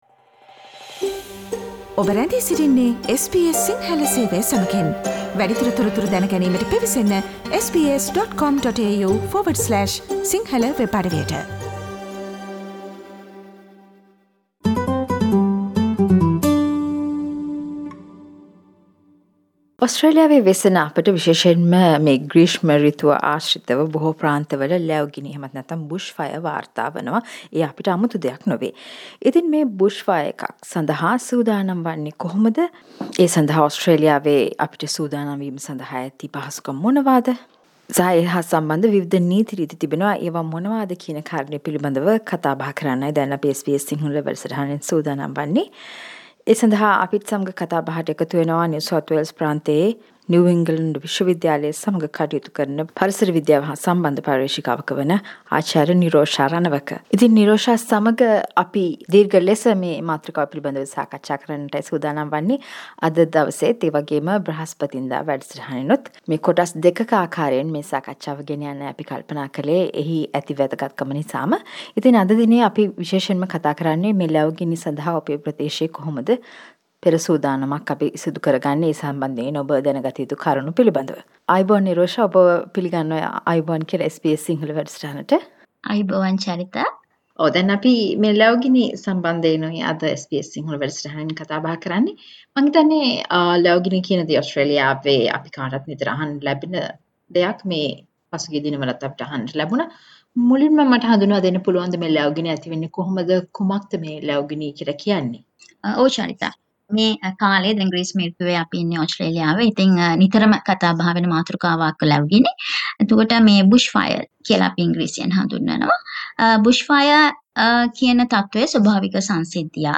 A discussion